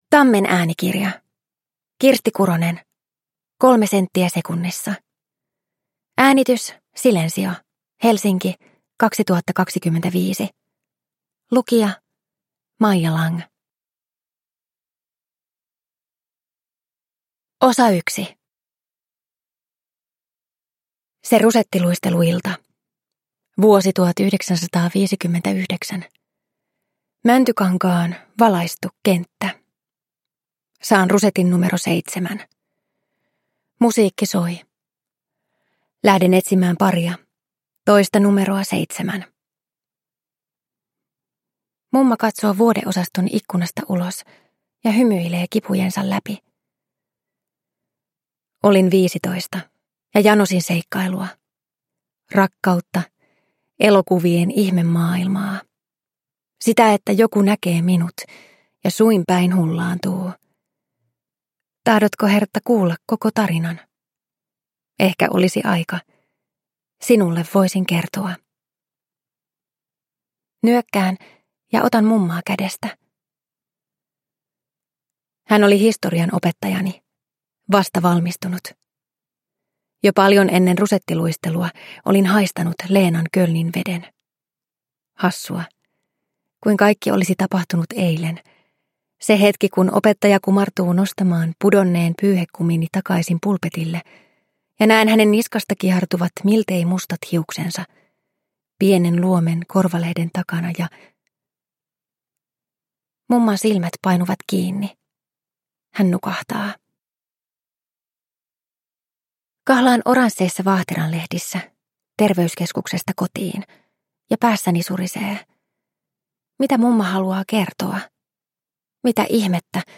Kolme senttiä sekunnissa – Ljudbok